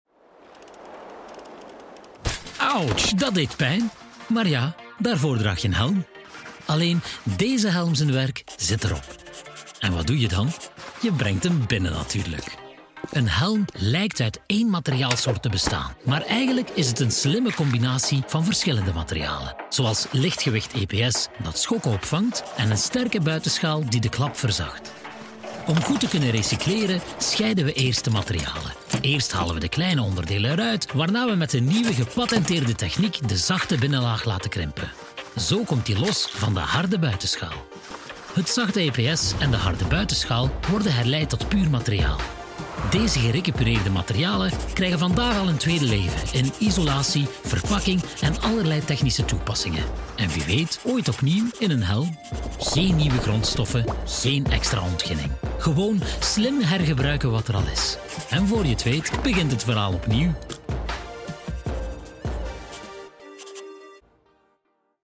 Accessible, Amicale, Douce, Jeune, Polyvalente
E-learning